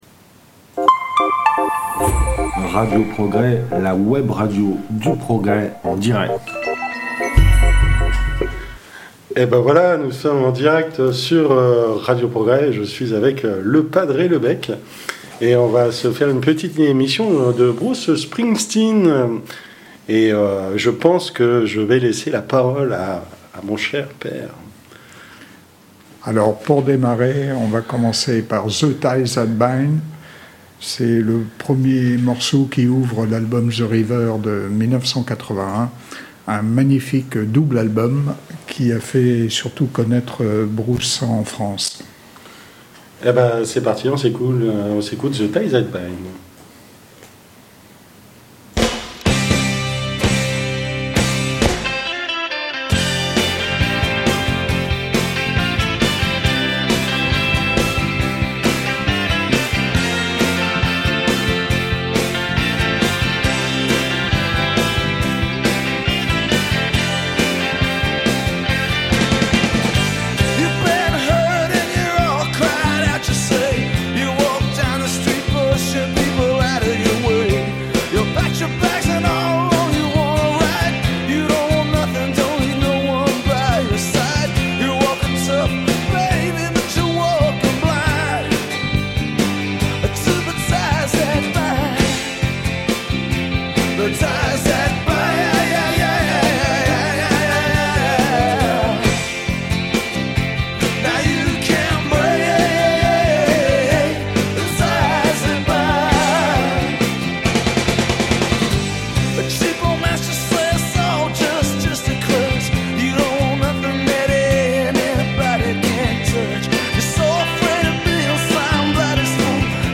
Grand entretien